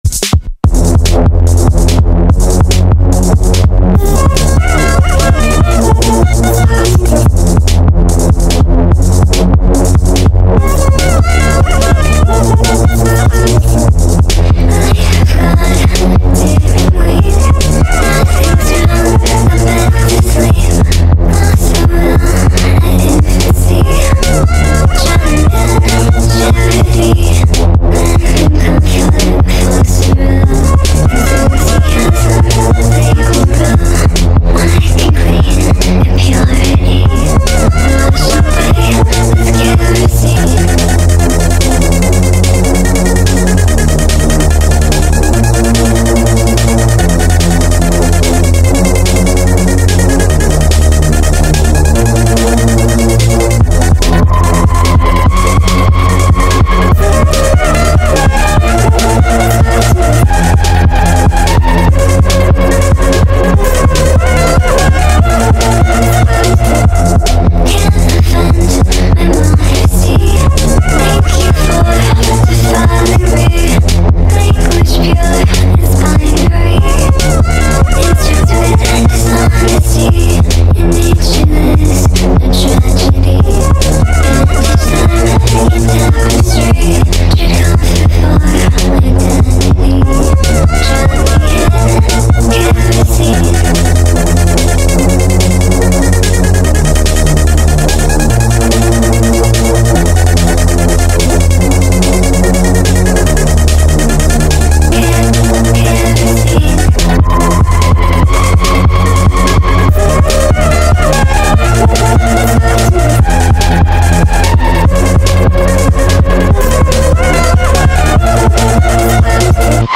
• Качество: 320 kbps, Stereo
sped up remix